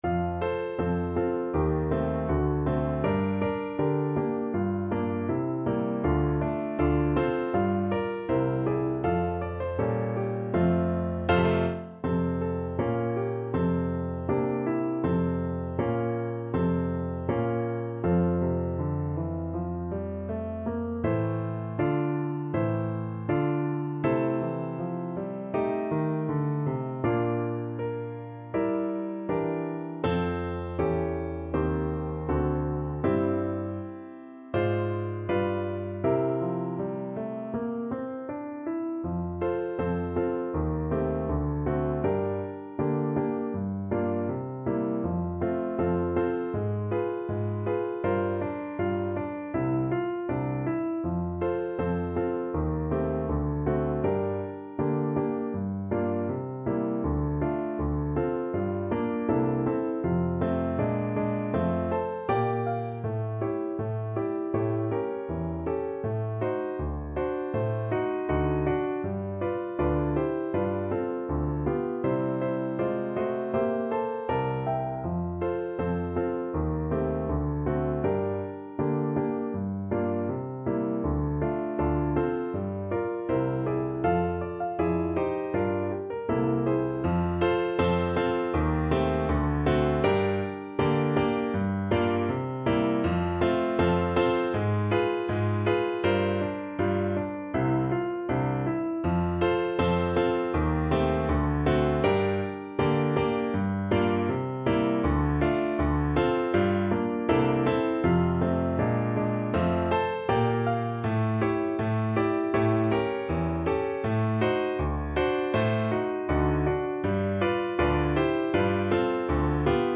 French Horn version
2/2 (View more 2/2 Music)
~ = 100 Moderato =80
E4-F5
Pop (View more Pop French Horn Music)